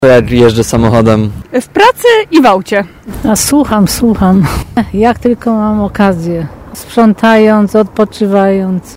Zapytaliśmy mieszkańców Tarnowa kiedy najchętniej słuchają radia.